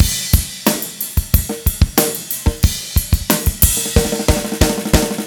12 rhdrm91snare.wav